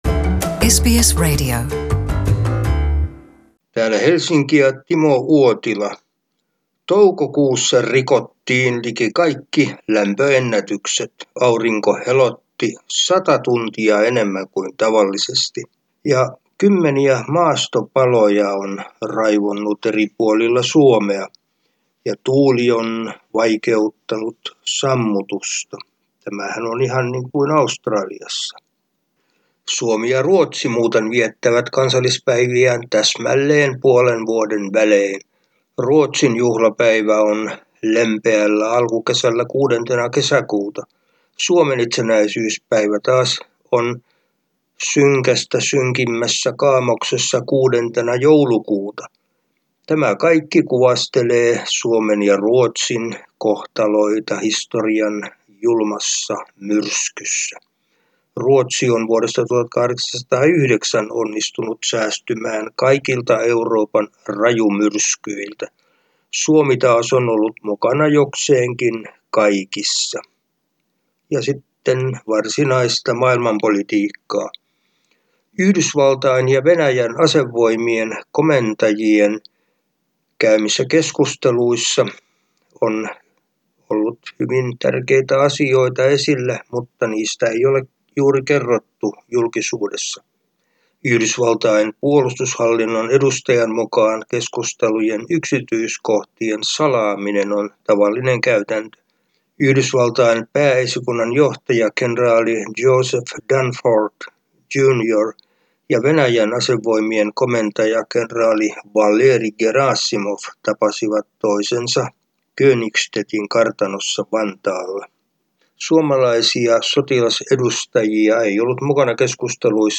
ajankohtaisraportti Suomesta